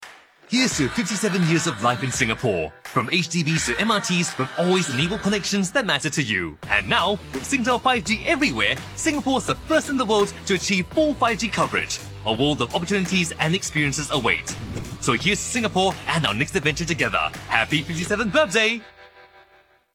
Adult (30-50) | Yng Adult (18-29)
Radio & TV Commercial Voice